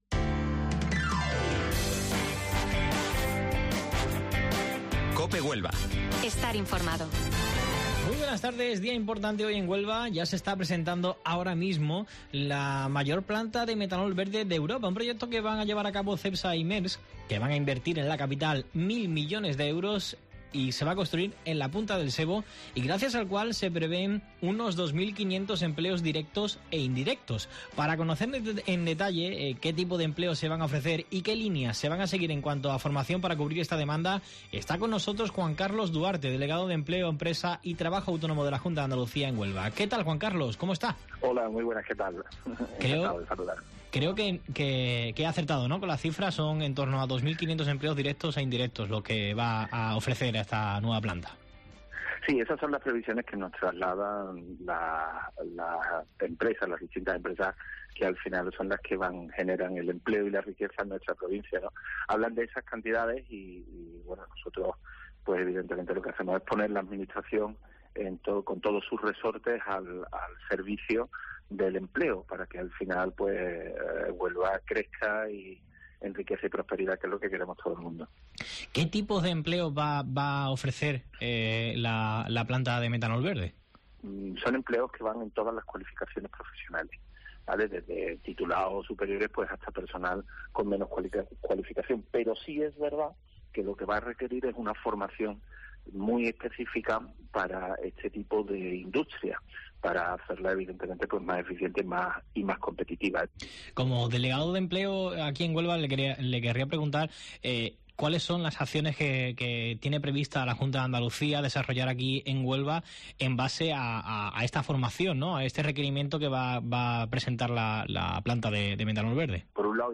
El delegado de Empleo de la Junta en Huelva, Juan Carlos Duarte, visita Herrera en COPE para contar el plan de formación de cara a la construcción de la planta de metanol verde.